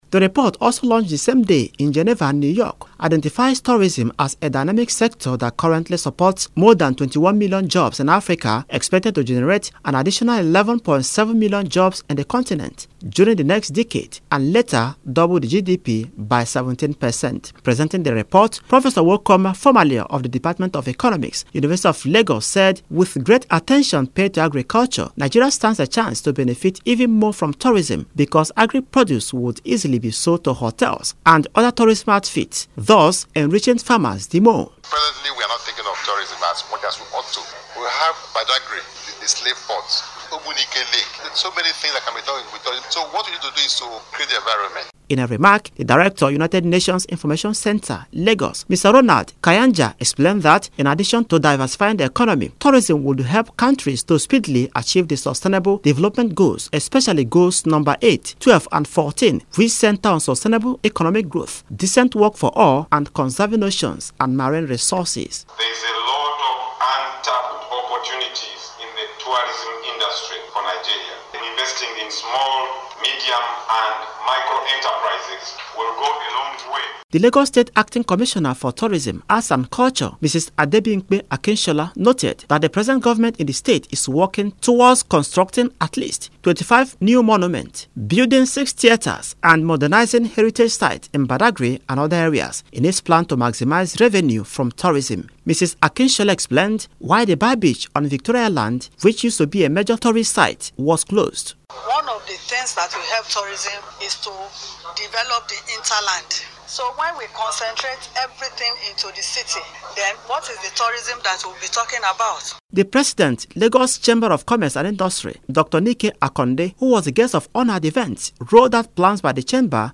Radio Report: Tourism as panacea to accelerated development